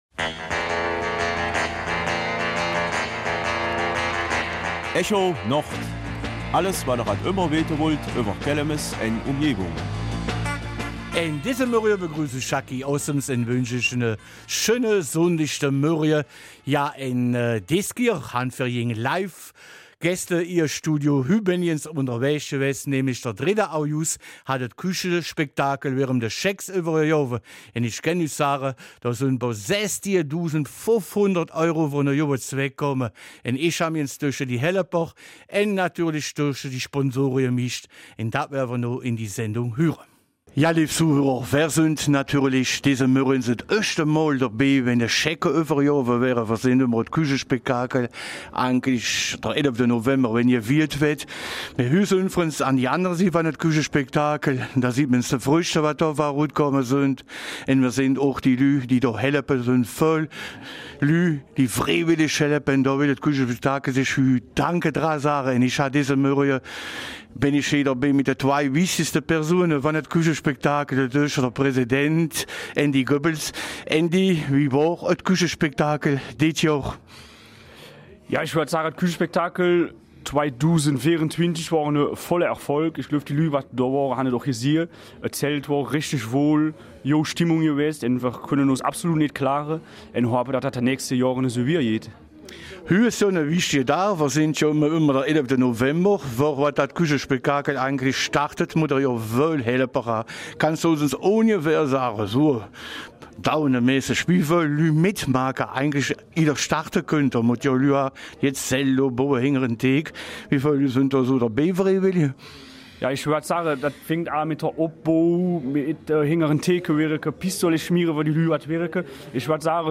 Kelmiser Mundart - 7.